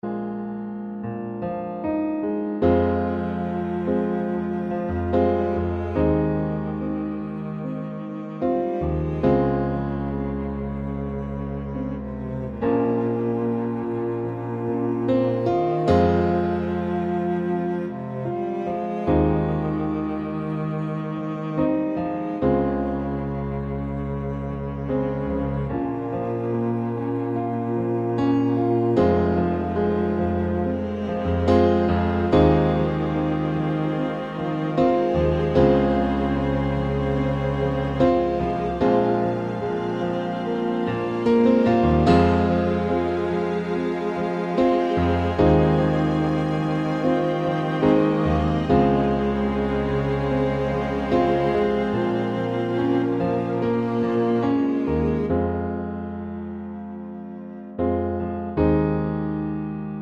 Acoustic Version Pop (2010s) 5:38 Buy £1.50